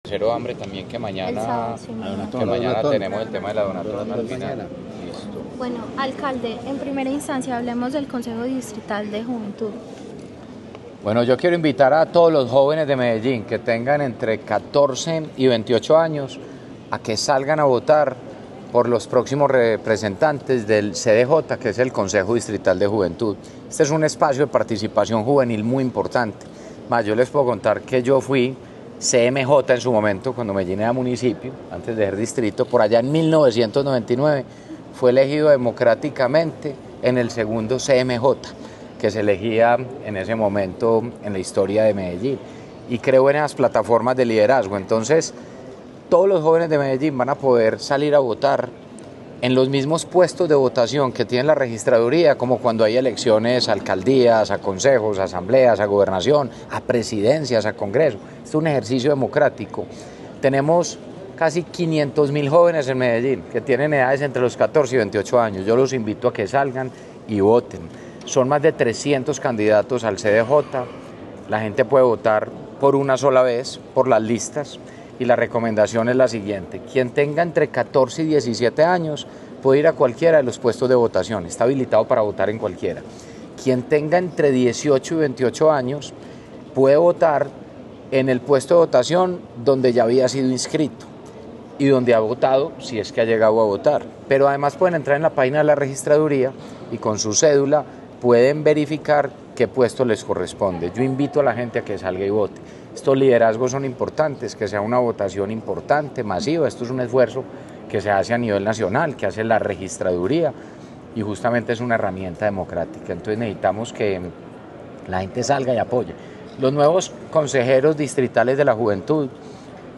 Declaraciones-alcalde-de-Medellin-Federico-Gutierrez-Zuluaga-3.mp3